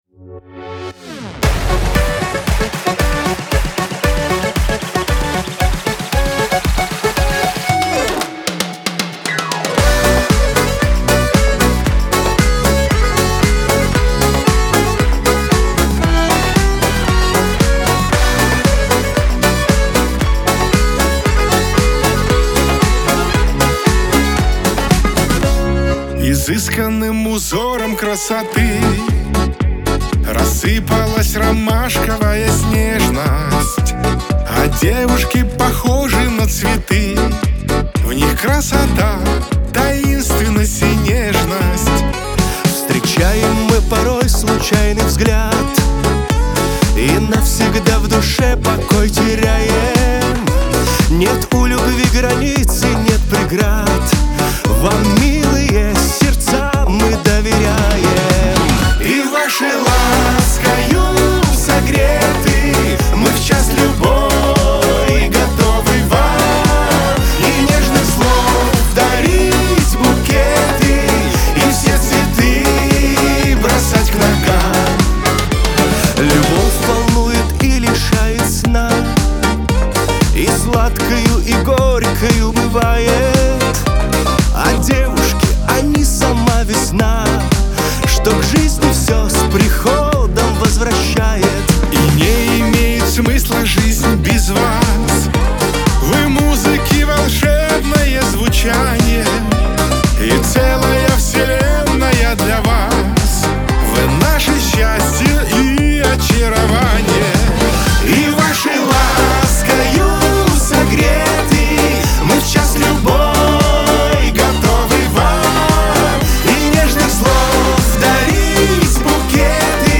диско
дуэт
pop
эстрада